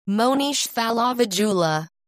Pronouncing my name